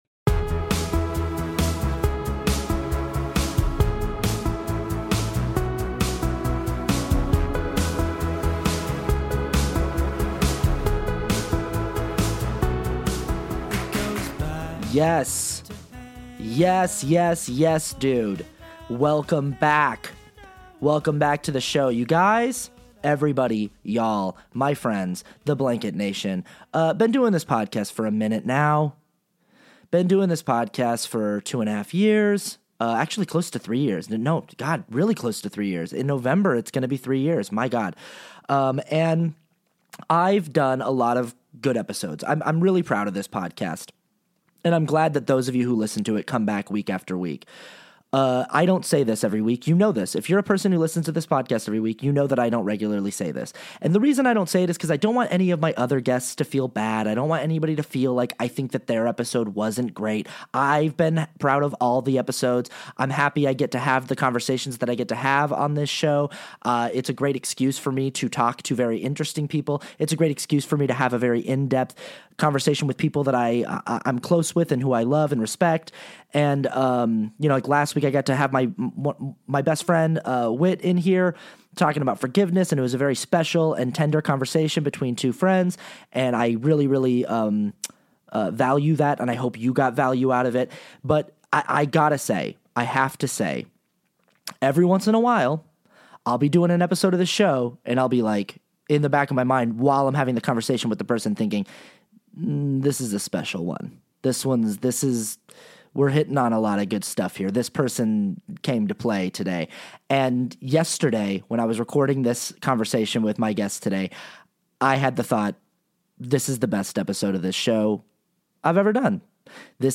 But today he's live and in person in the blanket fort sharing his journey to freedom and to a life of service and spiritual healing.